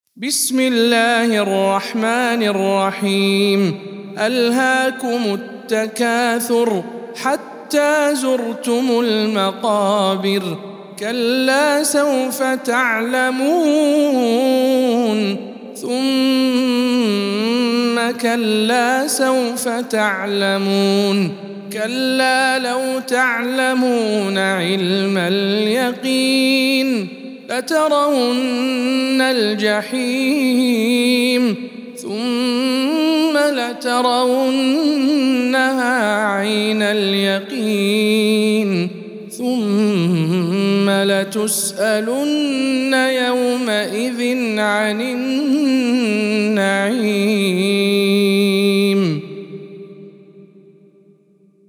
سورة التكاثر - رواية رويس عن يعقوب